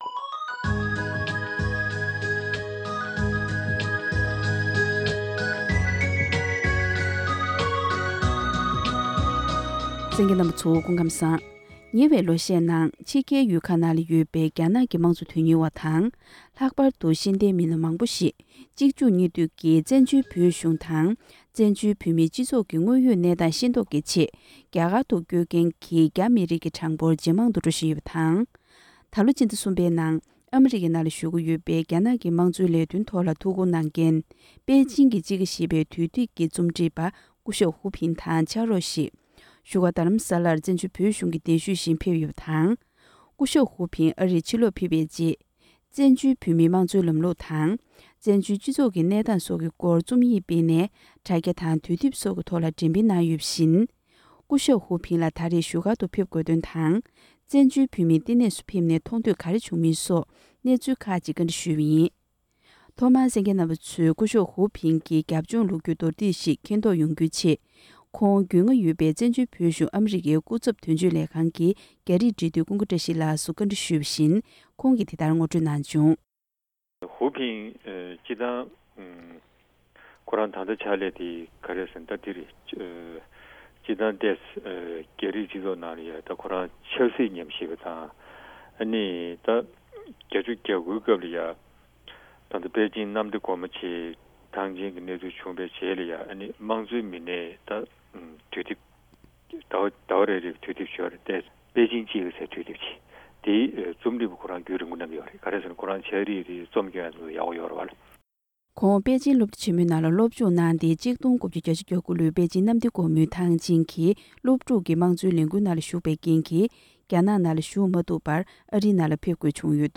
ཁོང་ལ་བཙན་བྱོལ་བོད་མིའི་སྤྱི་ཚོགས་ནང་མཐང་ཐོས་སོགས་ཀྱི་སྐོར་བཀའ་འདྲི་ཞུས་པའི་ལེ་ཚན་དང་པོ་དེ